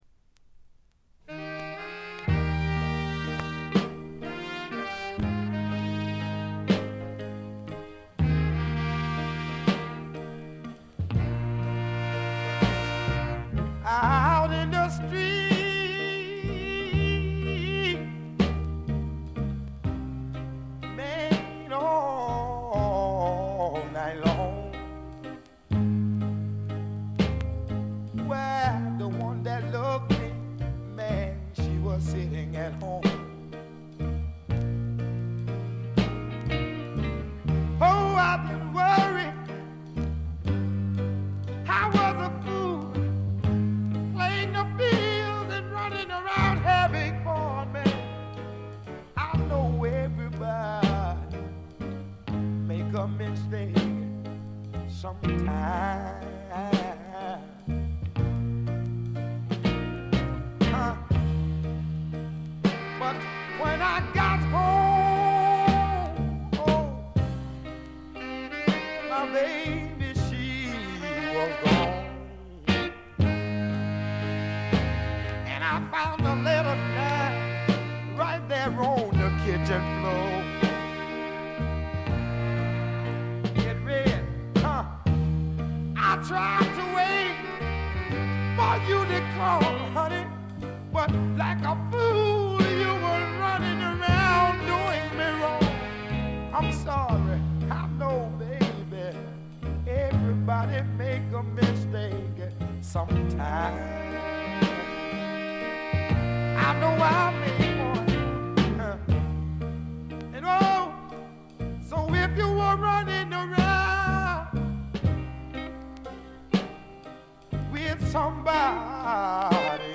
ところどころでチリプチ多め。散発的なプツ音多め。
試聴曲は現品からの取り込み音源です。